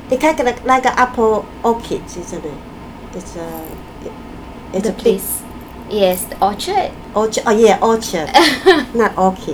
S1 = Malaysian female S2 = Taiwanese female Context: They are talking about places to visit in Cambridge during the summer, especially places to go and have tea.
S2 : orch- oh yeah. orchard S1 : @@ S2 : not orchid Intended Word : orchard Discussion : S2 intends to say orchard but says orchid instead.